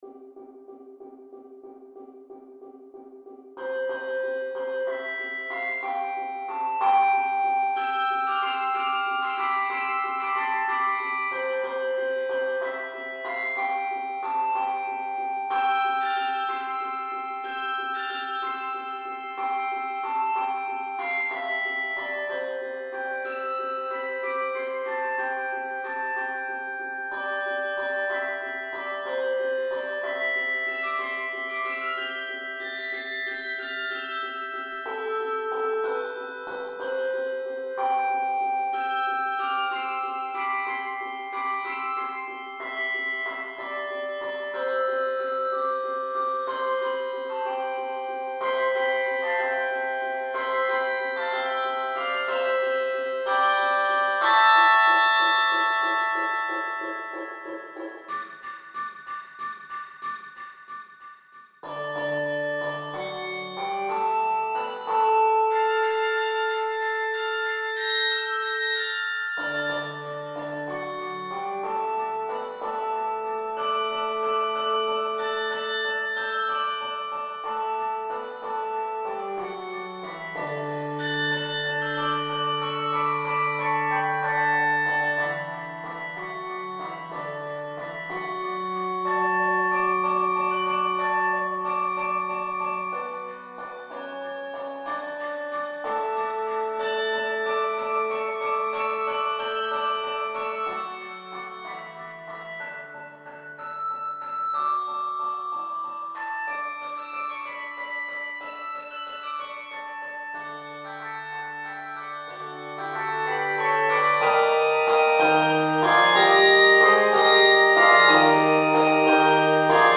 Keys of C Major, D Major, and Eb Major.